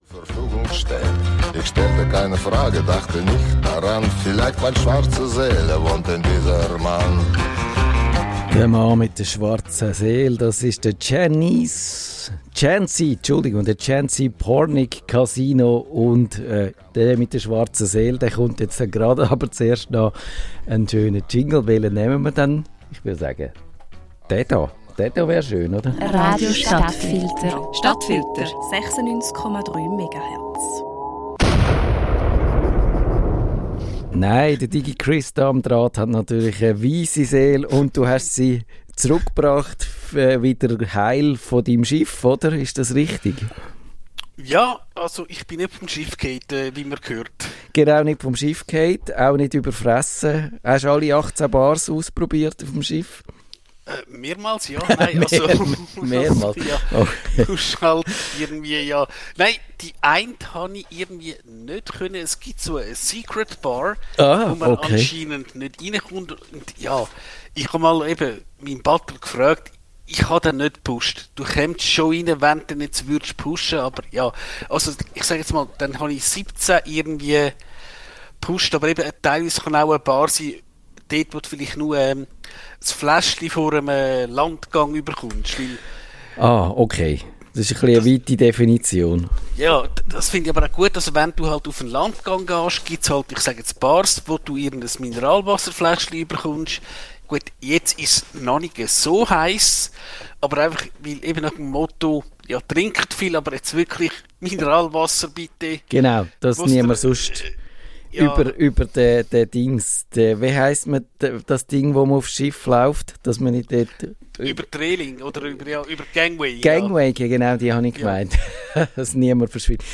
Der Extremsportler im Gespräch 38:38